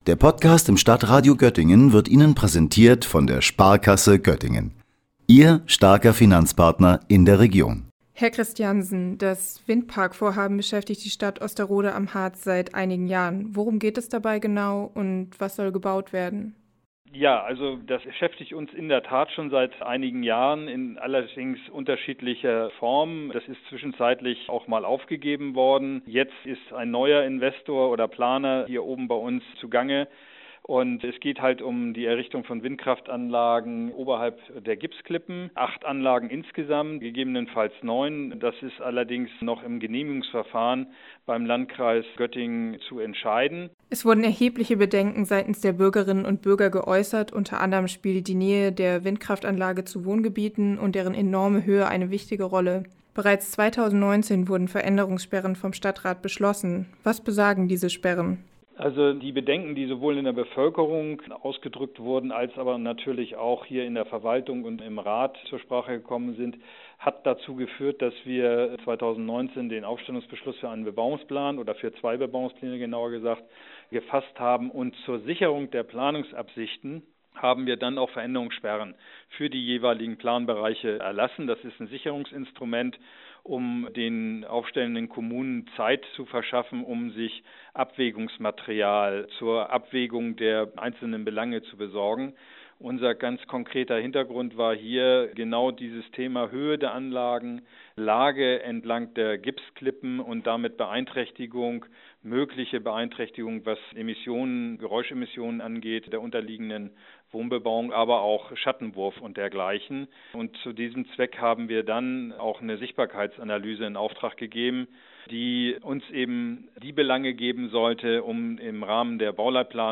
Nun hat der Osteroder Stadtrat zusammen mit den Investoren im April eine Kompromisslösung vereinbart, die den Bürger*innen die Bedenken nehmen soll. Was dieser sogenannte „Städtebauliche Vertrag“ vorsieht und was das für den Bau der Anlage zu bedeuten hat, erzählt der erste Städterat Thomas Christiansen im Interview